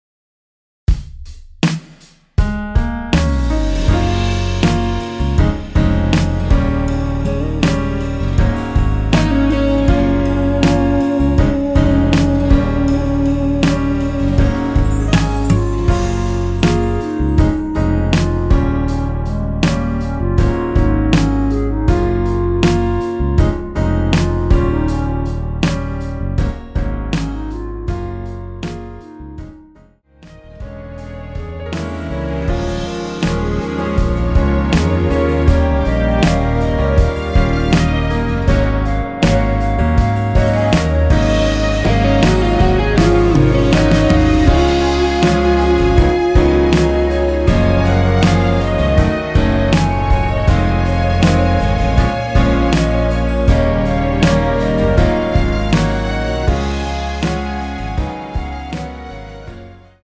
원키에서(-3)내린 멜로디 포함된 MR 입니다.(미리듣기 참조)
Db
앞부분30초, 뒷부분30초씩 편집해서 올려 드리고 있습니다.
중간에 음이 끈어지고 다시 나오는 이유는